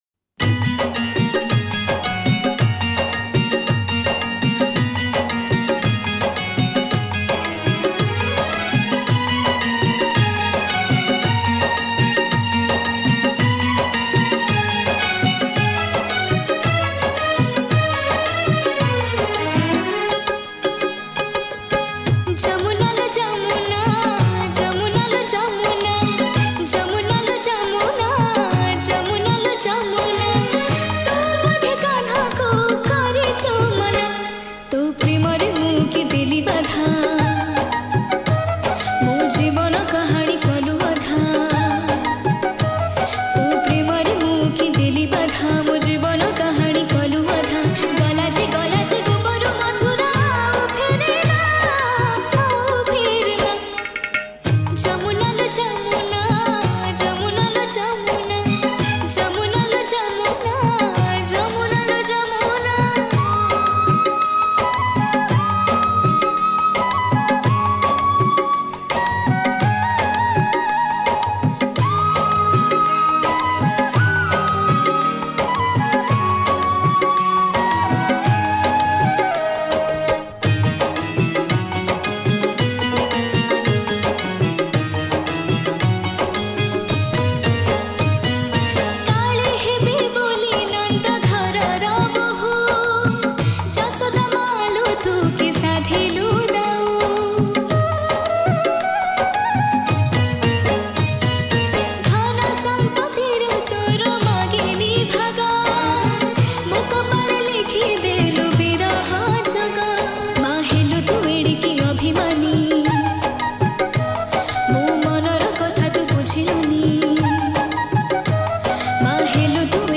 Odia Bhajan
Category: Odia Bhakti Hits Songs